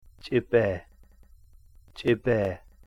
tc'ibee/tc'uubee ("Douglas Fir tree"). It is pronounced as in English "boot", but is short in duration.